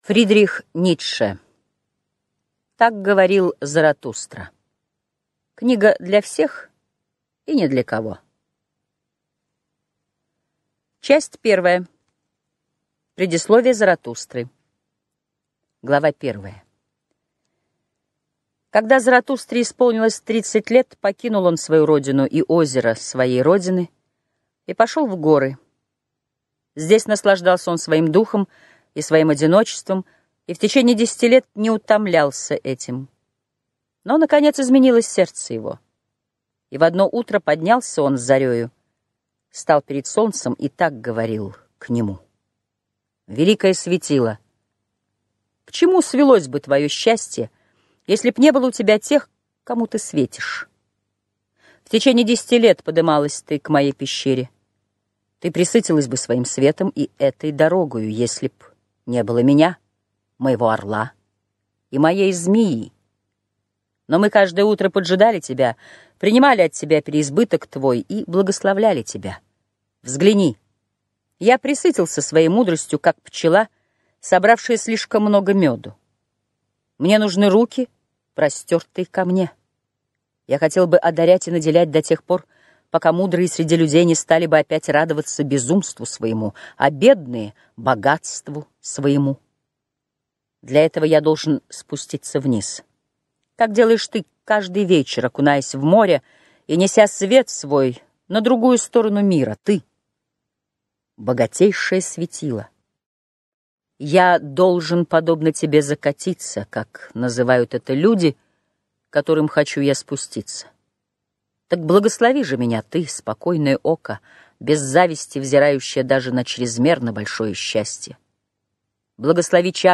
Аудиокнига Так говорил Заратустра - купить, скачать и слушать онлайн | КнигоПоиск